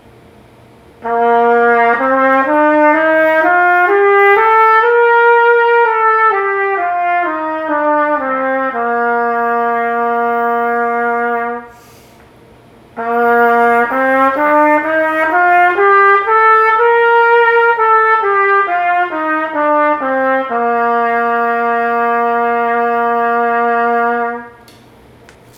例えばB♭音階を4分音符tempo100で1オクターブ演奏した場合
上向系はクレシェンド、下降系はデクレシェンド
【つながりが良い例】